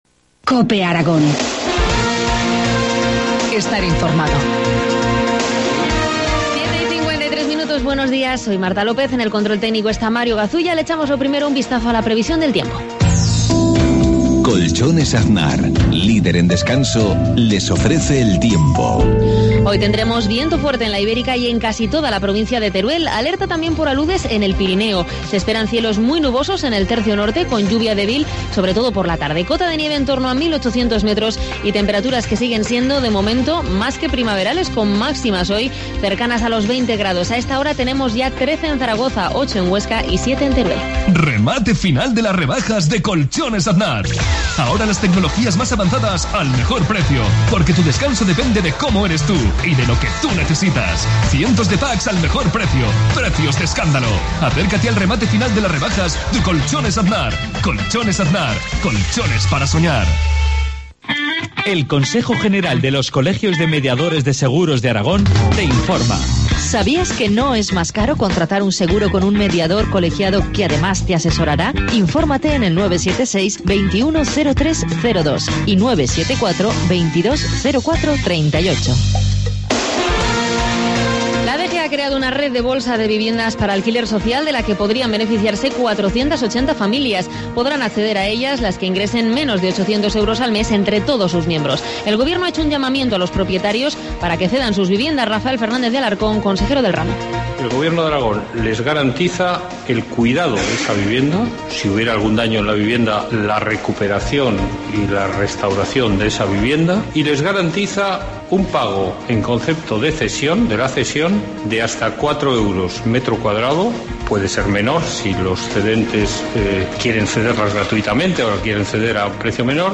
Informativo matinal, viernes 8 de marzo, 7.53 horas